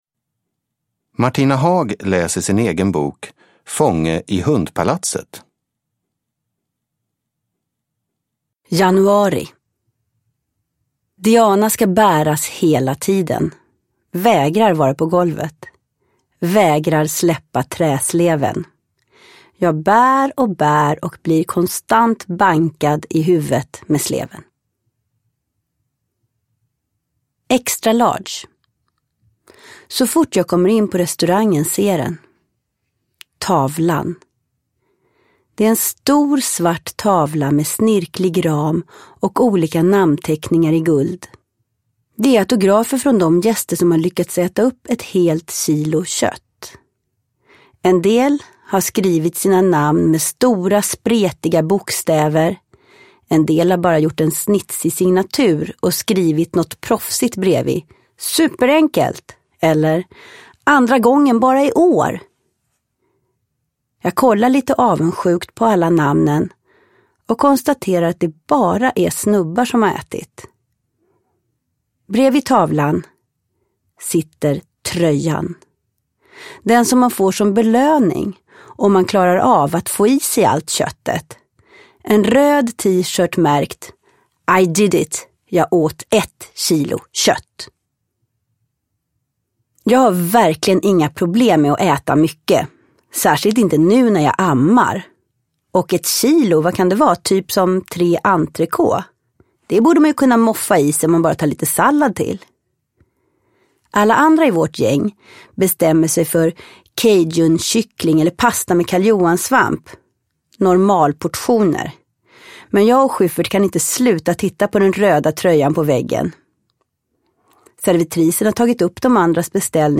Fånge i Hundpalatset / Ljudbok
Uppläsare: Martina Haag
Ljudbok